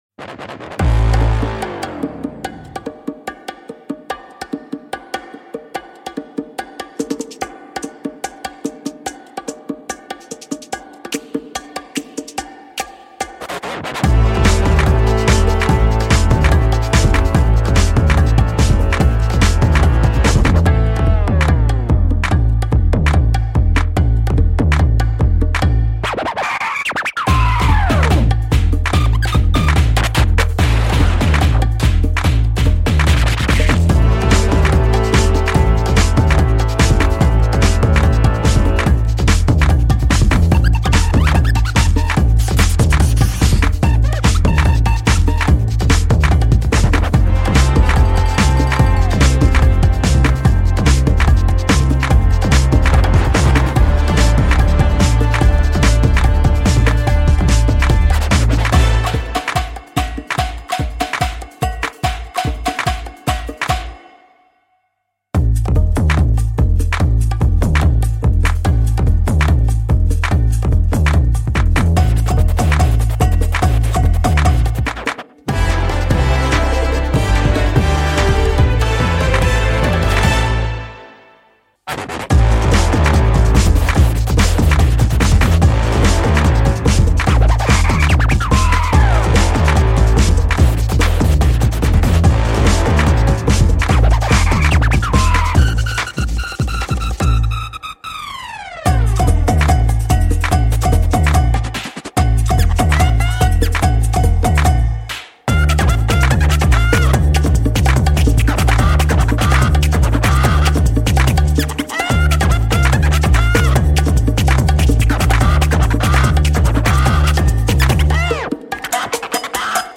saillies cuivrées, hip hop, rock et électro de toutes sortes